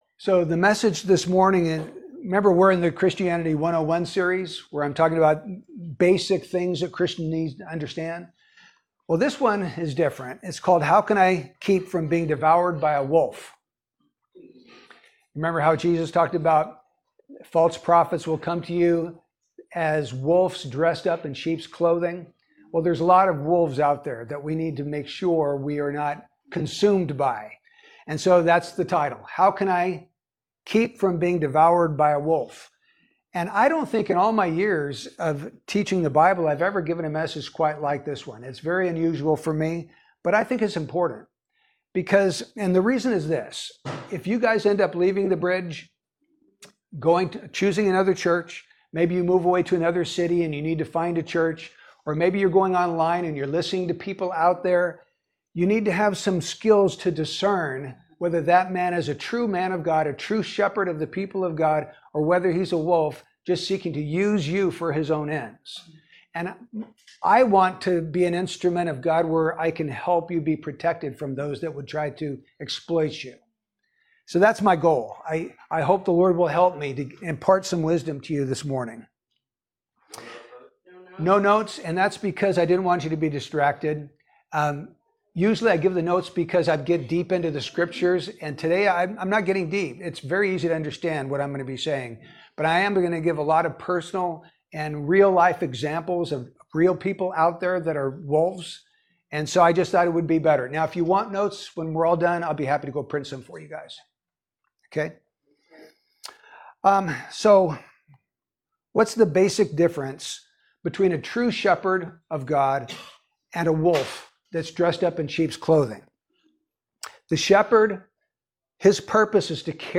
Series: Topical Messages